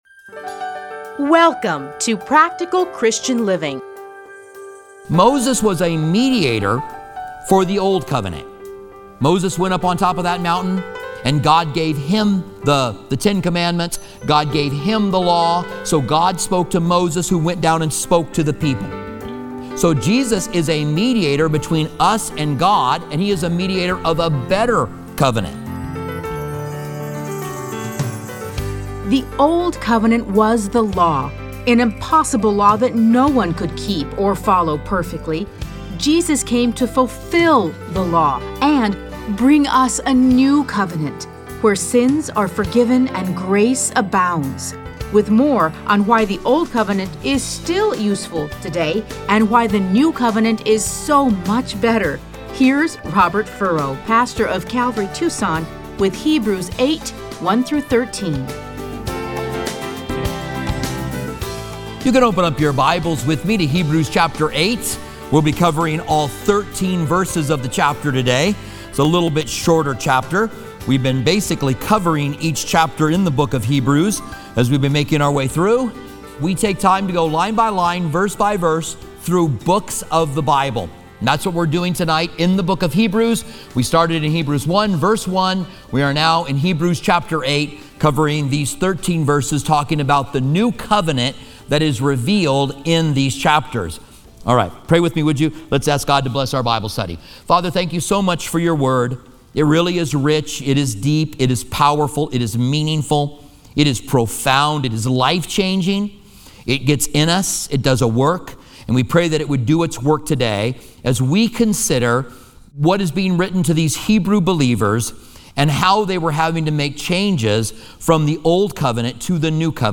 Listen to a teaching from Hebrews 8:1-13.